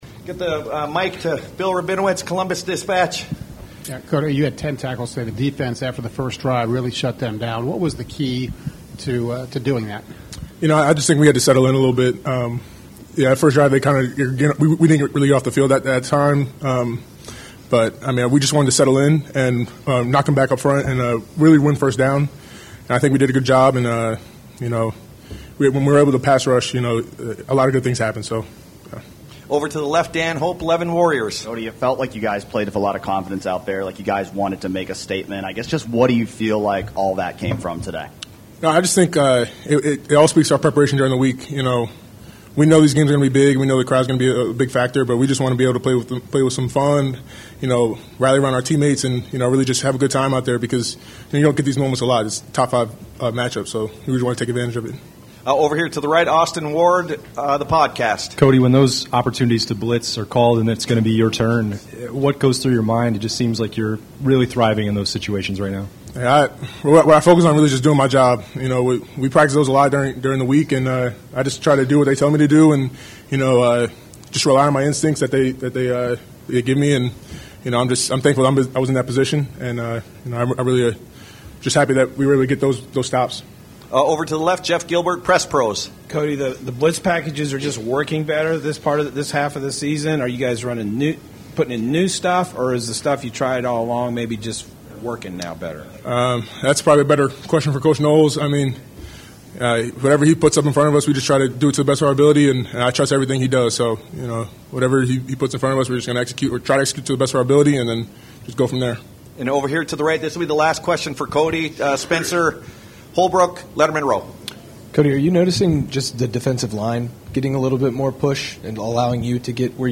Postgame Press Conference